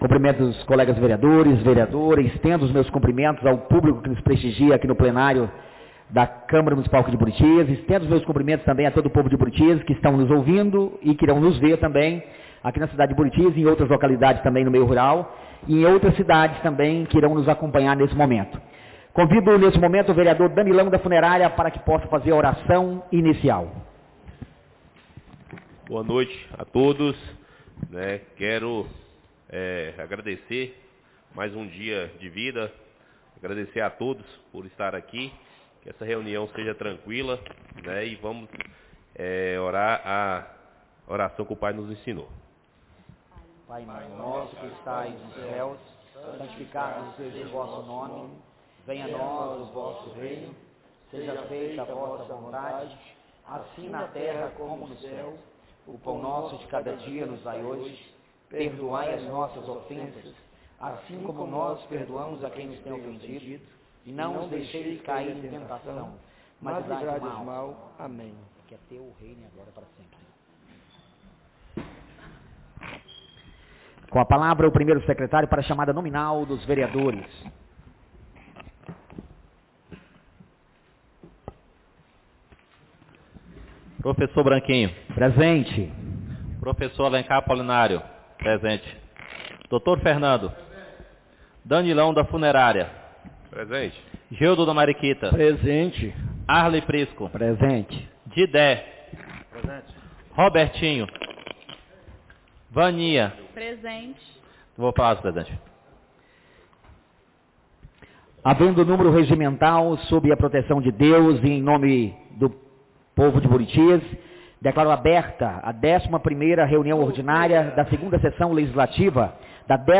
11ª Reunião Ordinária da 2ª Sessão Legislativa da 16ª Legislatura - 13-04-26